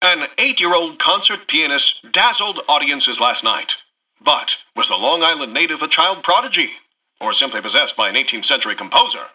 Newscaster_headline_31.mp3